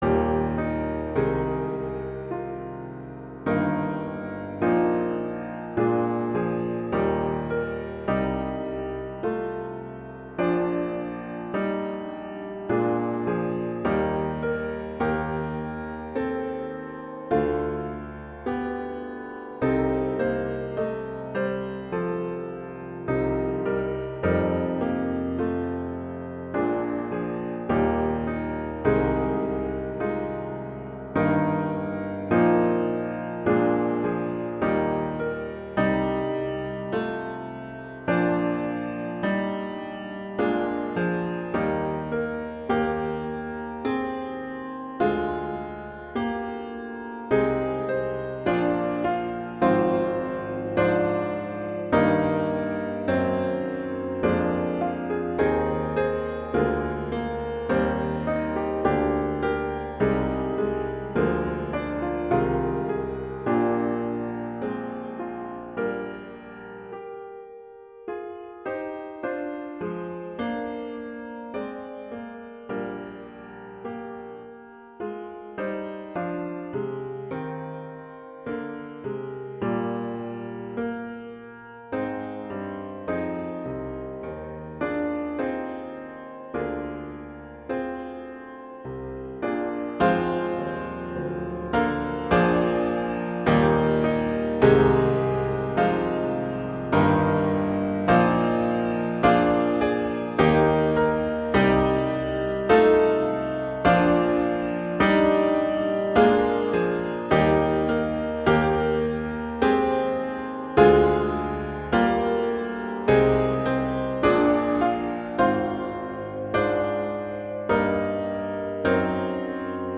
classical, patriotic, festival
Eb major
♩=52 BPM